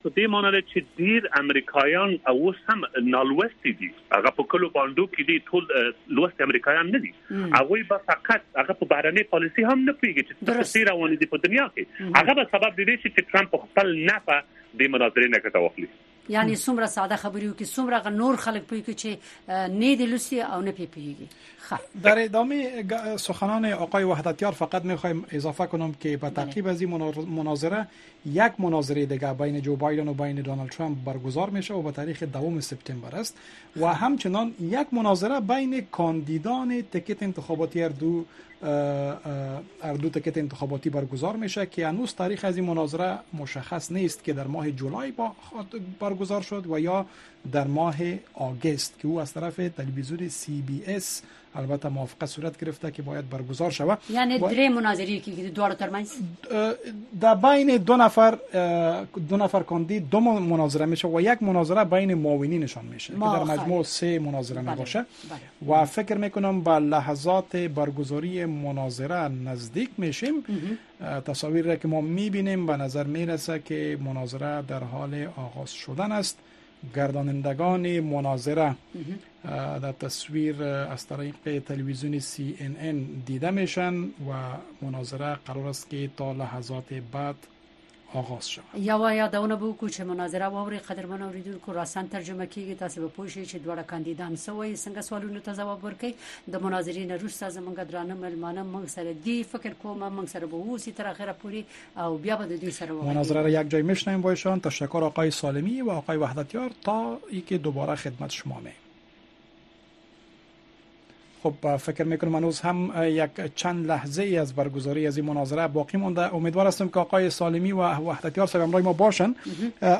مناظرهٔ نامزدان انتخابات ریاست جمهوری ایالات متحده در شبکه سی‌ان‌ان
جو بایدن و دونالد ترمپ نامزدان ریاست جمهوری ایالات متحده در نخستین مناظره به میزبانی شبکۀ خبری سی ان ان اشتراک کرده اند>